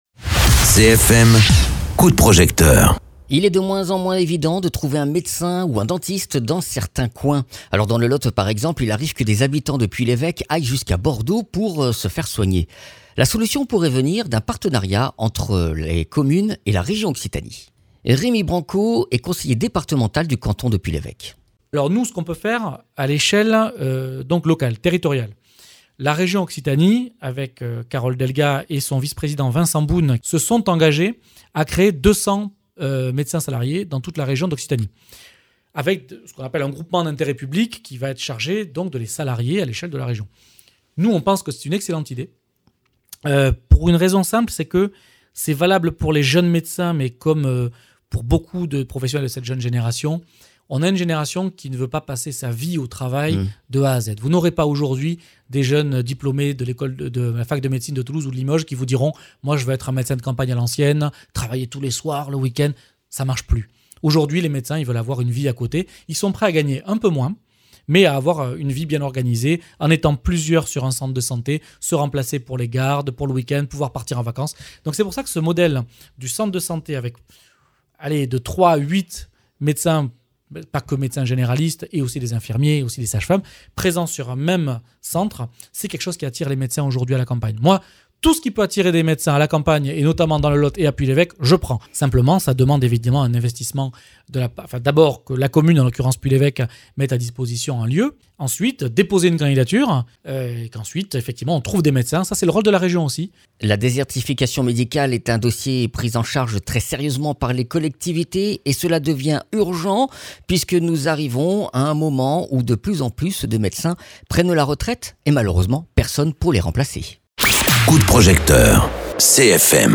Interviews
Invité(s) : Rémi Branco, conseillé départemental du canton de Puy l’Evêque (46)